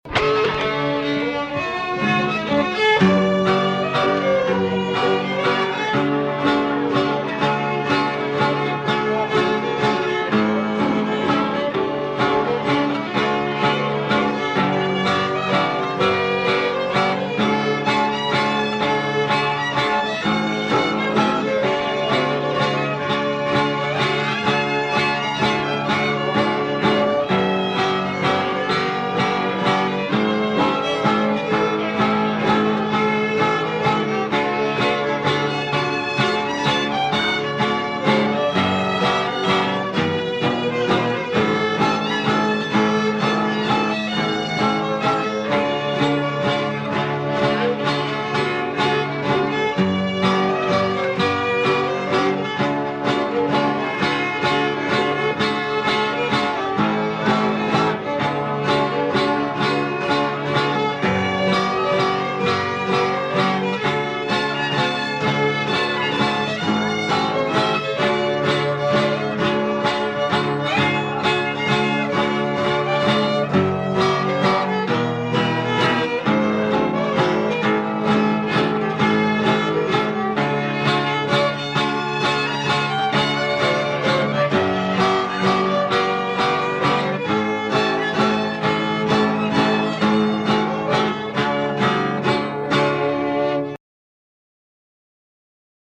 Set in the key of D, this one great waltz that every Midwestern fiddler should know.
at O.A.K. Towers in Columbia, MO, back in 1975. Note the thumb-pick guitar styling, which was a hallmark (if you want to call it that) of Mid-Missouri fiddle accompaniment in the 60s & 70s.